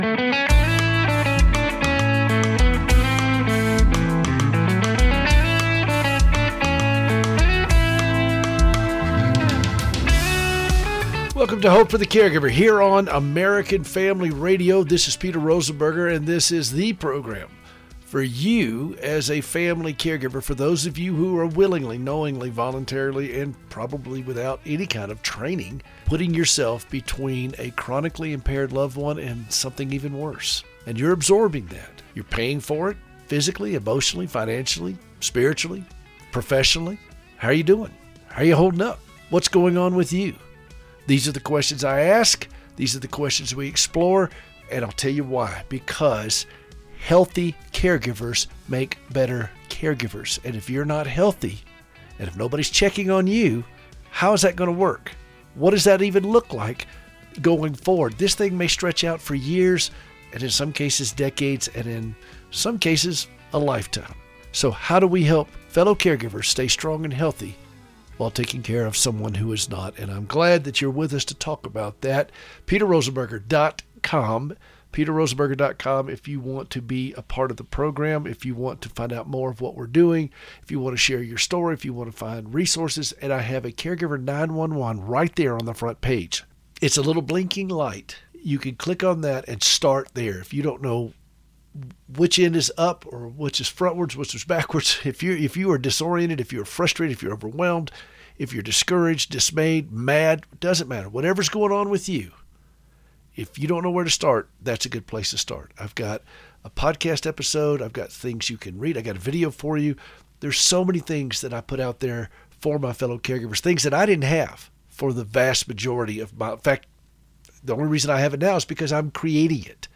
LIVE on Saturday mornings at 7:00AM.